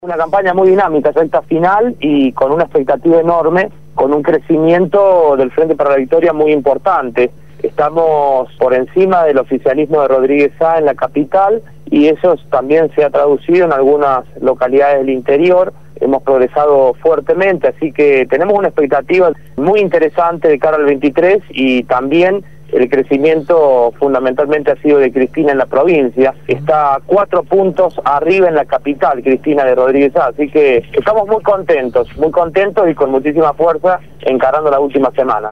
En conversación telefónica